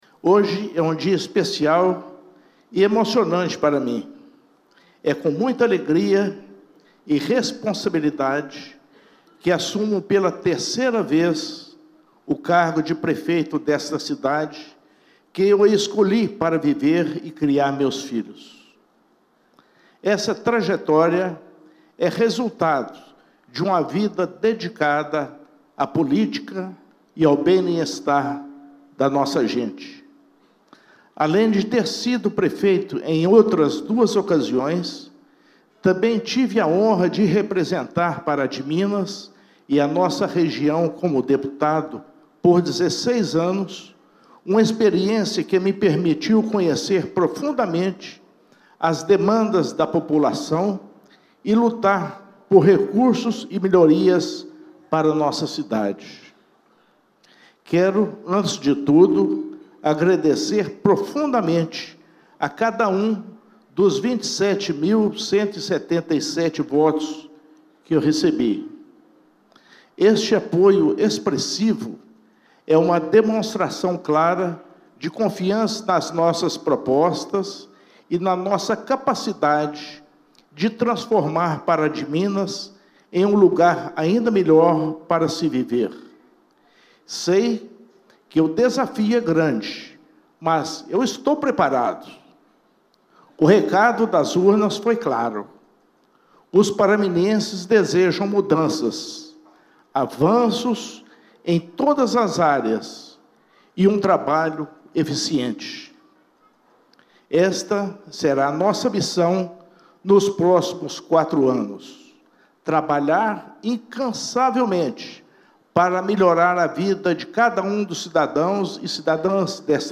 Inácio Franco e Luiz Fernando de Lima assumiram o comando do Município de Pará de Minas durante solenidade realizada no fim da tarde desta quarta-feira (1º), no Ápice Convenções e eventos, localizado no Bairro Providência, quando foram empossados vereadores para a legislatura 2025/2028, além do prefeito e vice-prefeito.
Em seu discurso, o prefeito Inácio Franco destacou sua alegria e satisfação ao assumir a comando do Município de Pará de Minas pela terceira vez, após cumprir quatro mandatos como deputado estadual na Assembleia Legislativa de Minas Gerais (ALMG).